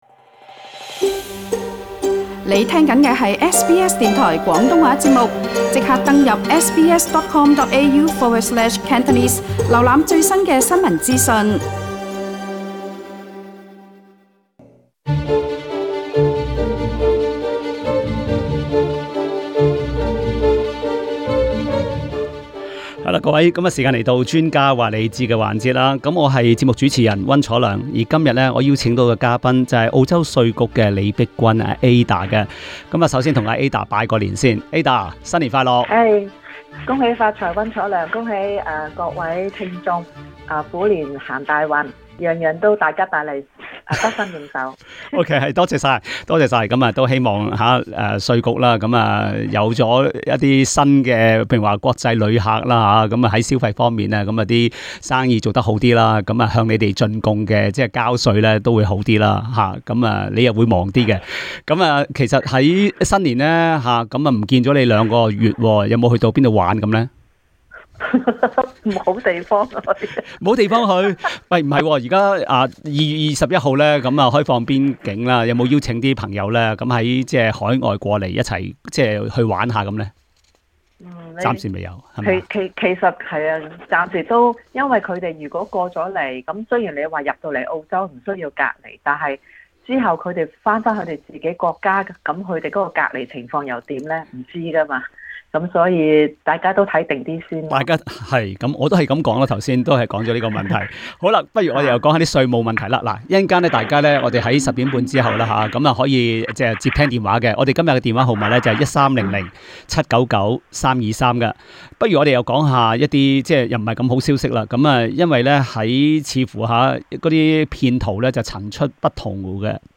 READ MORE 緊記買賣股票時要做記錄 【專家話你知】等齊自動填寫資料 可於 7 月後交報稅表 【專家話你知】稅務局呼籲大家小心受騙 瀏覽更多最新時事資訊，請登上 廣東話節目 Facebook 專頁 、 MeWe 專頁 、 Twitter 專頁 ，或訂閱 廣東話節目 Telegram 頻道 。